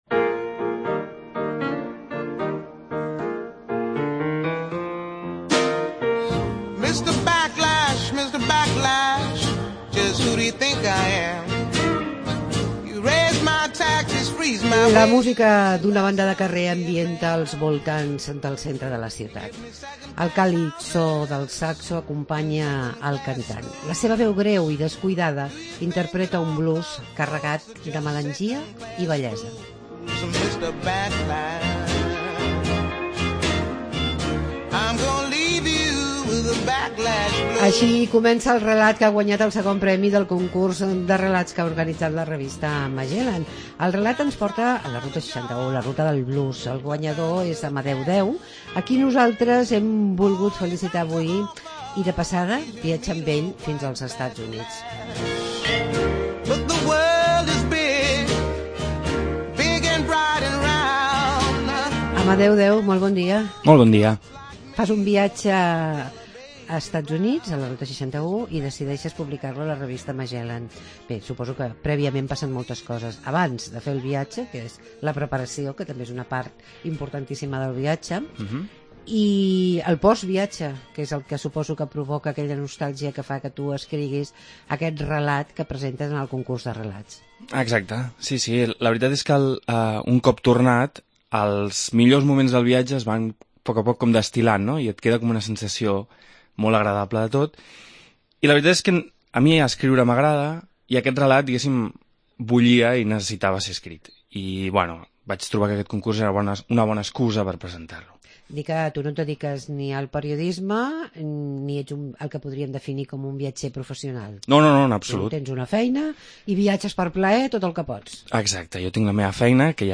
Entrevista amb un dels guanyadors del Concurs de Magellan